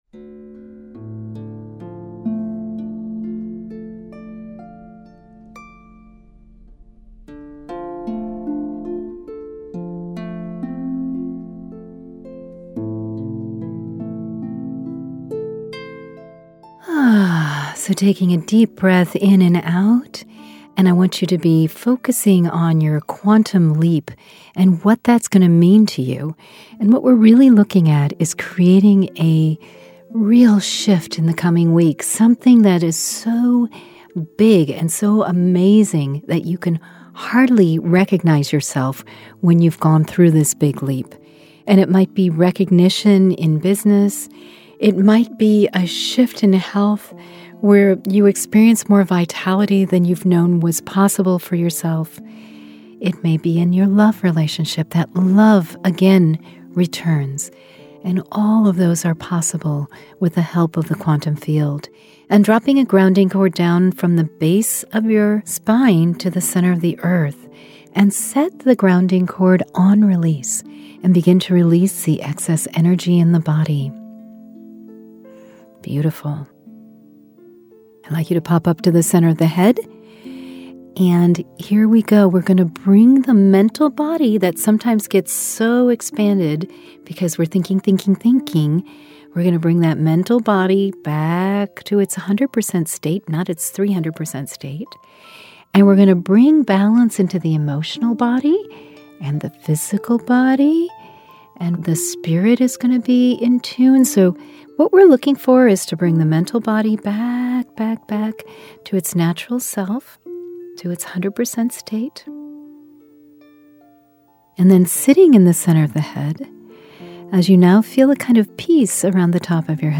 Q5 Meditations